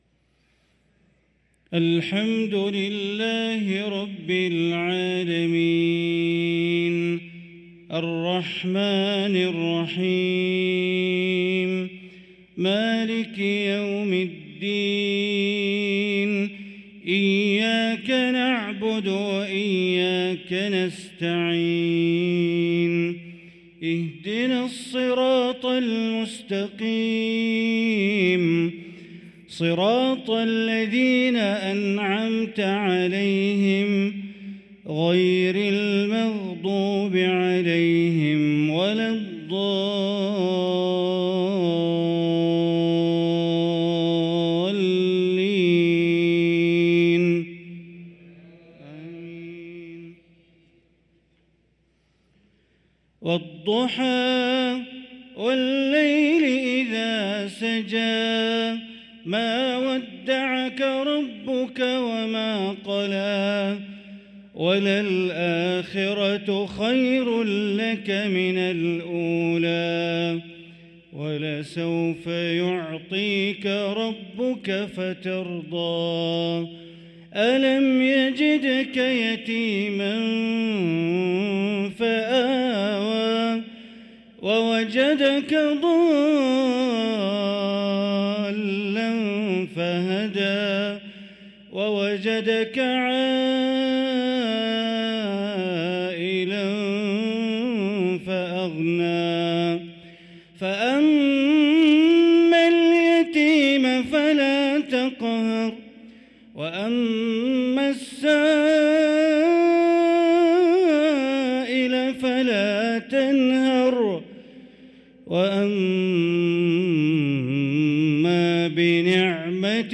صلاة العشاء للقارئ بندر بليلة 27 ربيع الأول 1445 هـ
تِلَاوَات الْحَرَمَيْن .